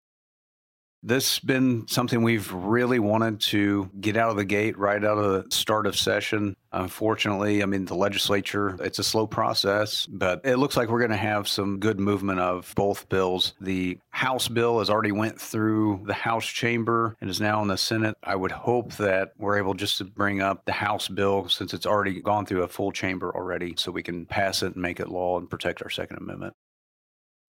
3. Senator Brattin also says both versions of the “Second Amendment Preservation Act” are read for full Missouri Senate discussion.